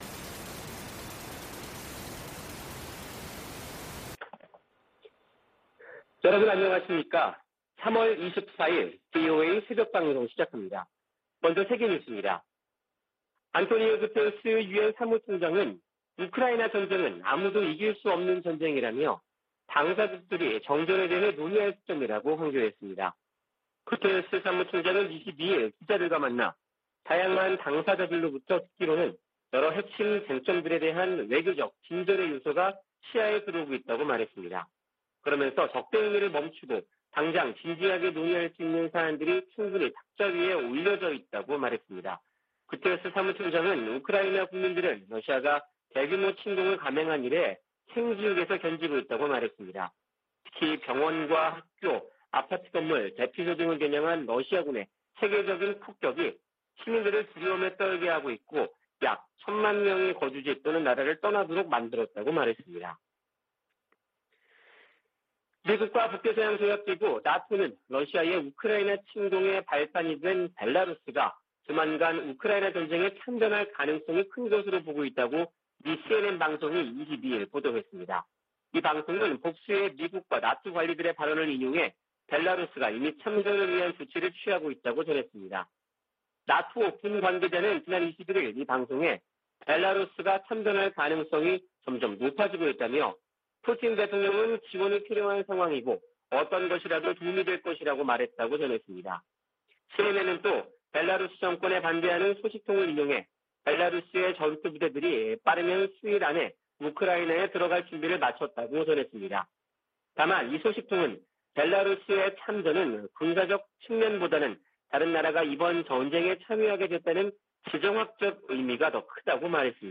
VOA 한국어 '출발 뉴스 쇼', 2022년 3월 24일 방송입니다. 북한 해킹 조직이 러시아 등 사이버 범죄자들과 협력하고 있다고 백악관 국가안보보좌관이 지적했습니다. 독자 대북제재를 강화하고 있는 조 바이든 미국 행정부는 지난 3개월간 20건이 넘는 제재를 가했습니다. 유엔 인권기구가 49차 유엔 인권이사회 보고에서 회원국들에 국내 탈북민들과 접촉하고 인권 유린 책임을 규명할 수 있게 보장해 줄 것을 촉구했습니다.